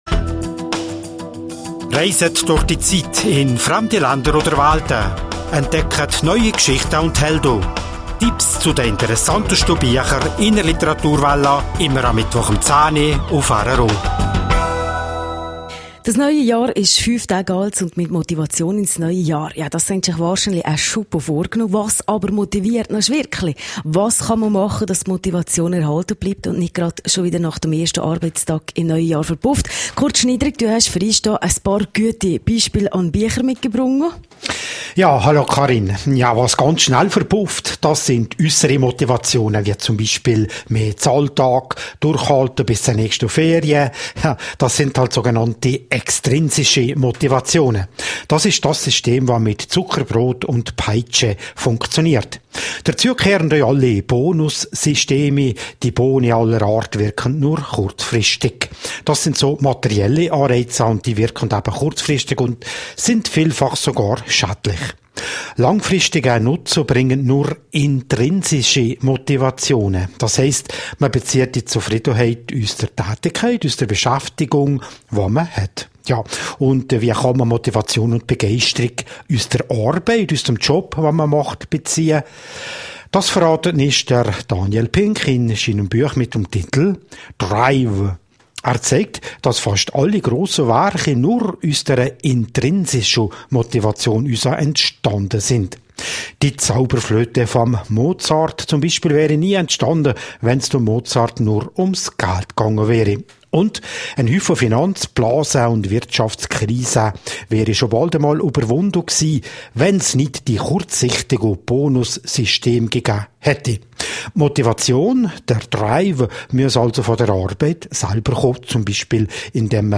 Literaturwälla